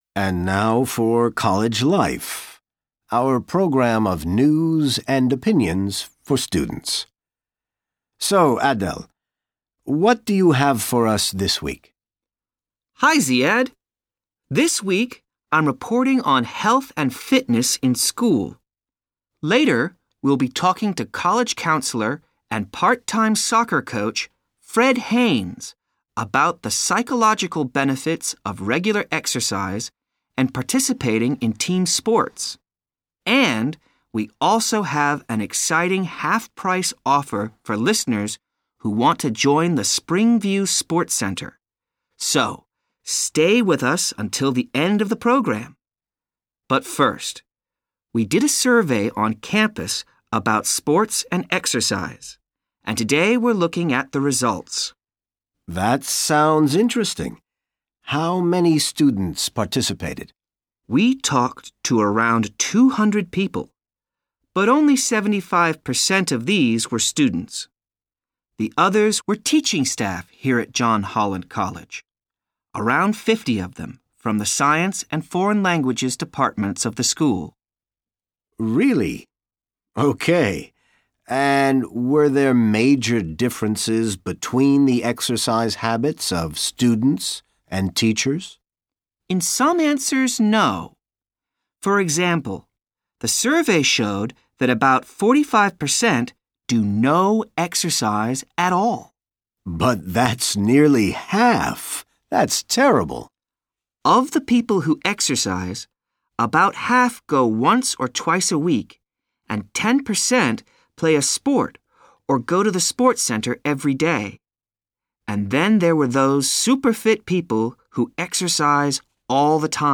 SECTION I: LISTENING
SECTION I: LISTENING In this section, you will hear a dialogue. You will hear the dialogue twice.